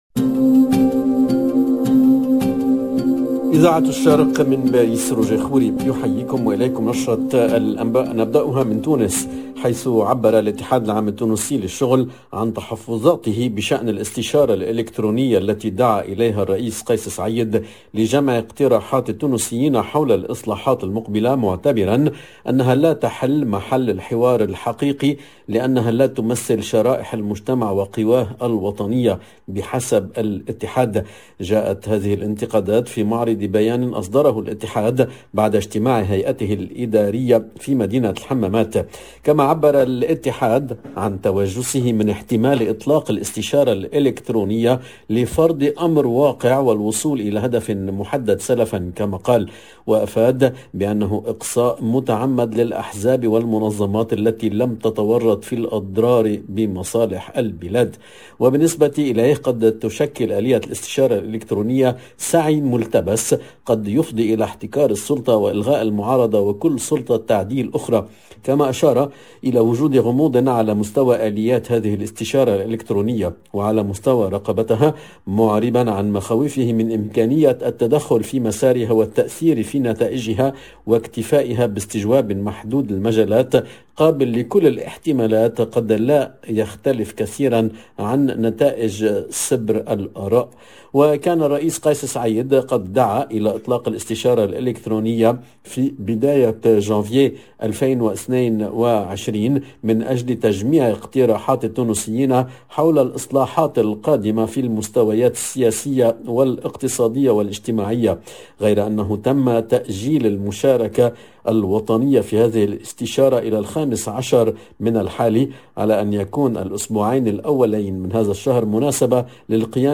LE JOURNAL DE MIDI 30 EN LANGUE ARABE DU 05/01/22